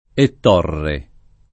ett0rre o